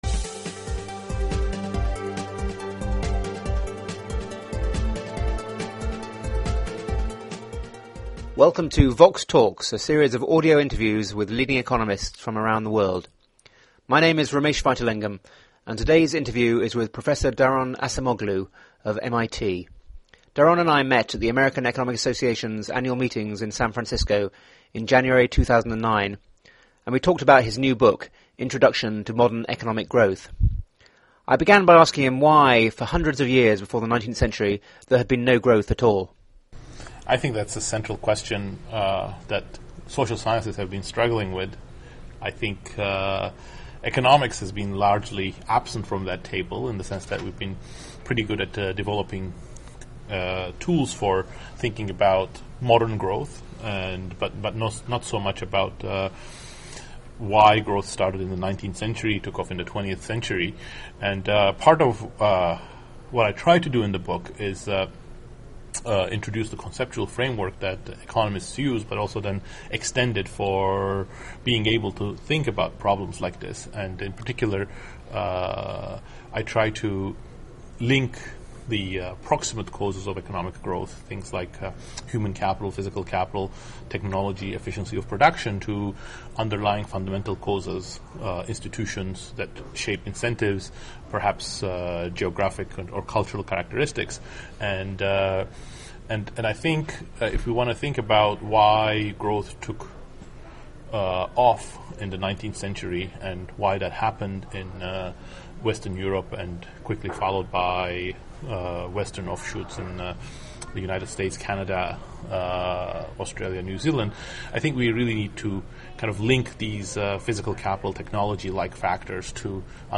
Daron Acemoğlu interviewed
They discuss why sustained growth took off in Europe in the nineteenth century, the roles of technology and institutions in explaining why some countries grow rapidly while others stagnate, and the growth prospects for the world’s poorest countries as well as the recent Asian success stories. The interview was recorded at the American Economic Association meetings in San Francisco in January 2009.